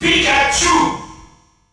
The announcer saying Pikachu's name in French releases of Super Smash Bros.
Pikachu_French_Announcer_SSB.wav